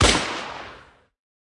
Media:Colt_base_atk_1.wav 攻击音效 atk 初级和经典及以上形态攻击音效
Colt_base_atk_1.wav